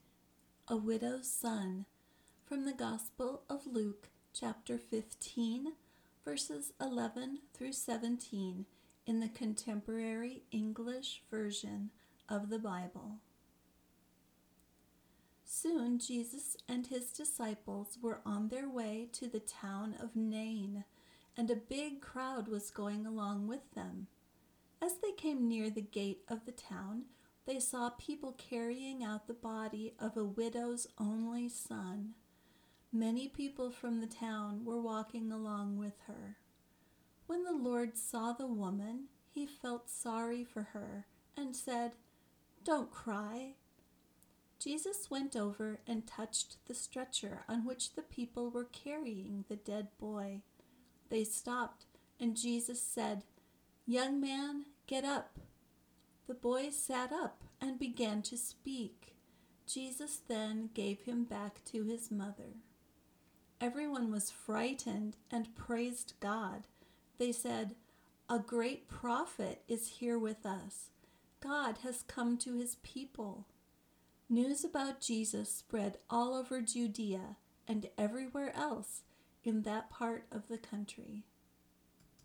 Listening Exercise
Do you hear the way English speakers put stress on certain words and syllables? Maybe you heard the way English intonation and stress is used in sentences with exclamation marks (!).